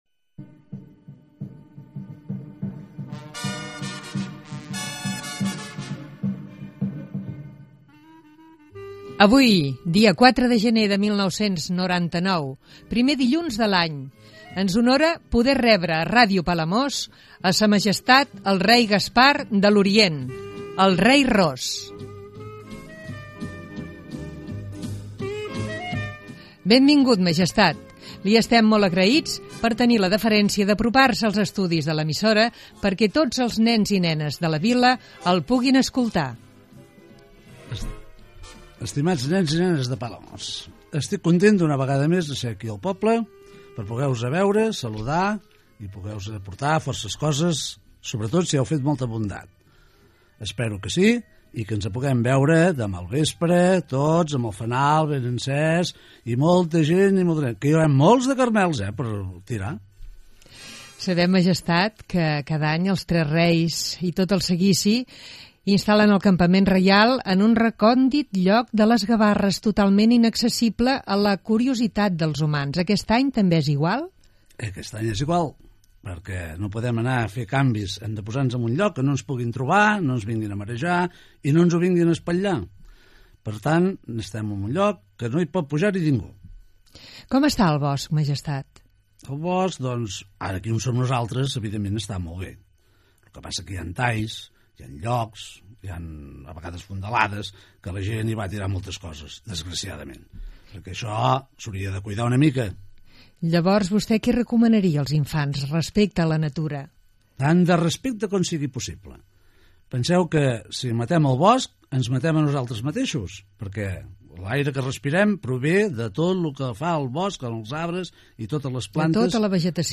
Data, entrevista al rei Gaspar de l'Orient
Divulgació